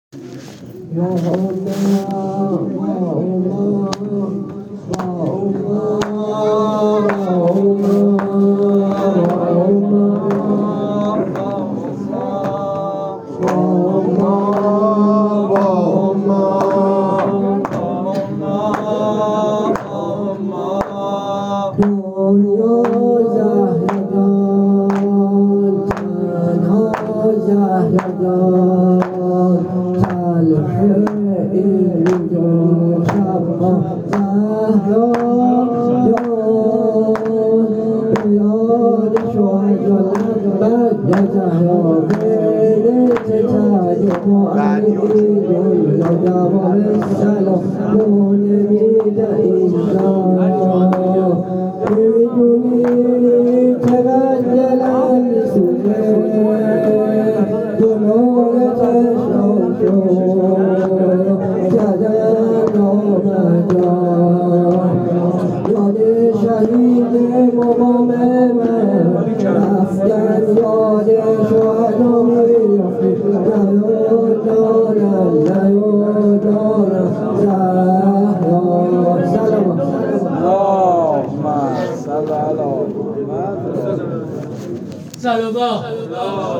مداح اهل بیت